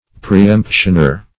Search Result for " preemptioner" : The Collaborative International Dictionary of English v.0.48: Preemptioner \Pre*["e]mp"tion*er\, n. One who holds a prior right to purchase certain public land.